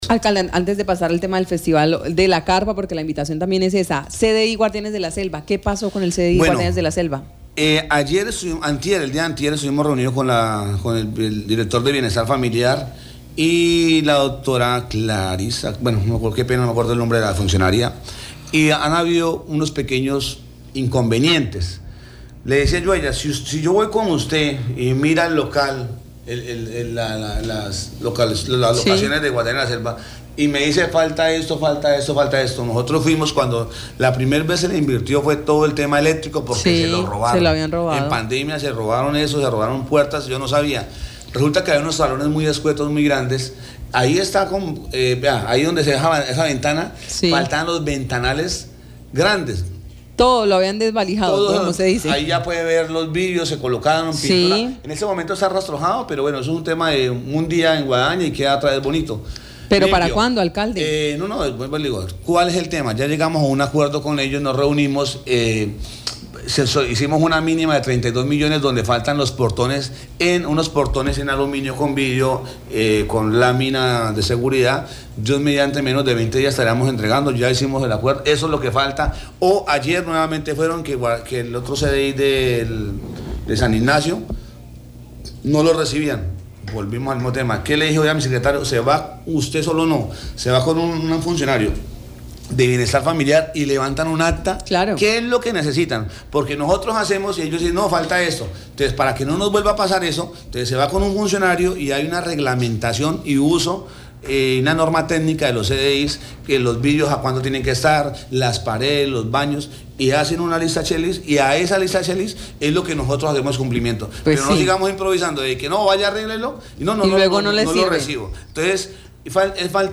Sobre el Centro de Desarrollo Infantil Guardianes de la Selva, el alcalde Ramón Guevara señaló que se ha venido realizando unas adecuaciones y recuperaciones de este espacio para en muy pocos días ponerlo al servicio de los niños de San José del Guaviare.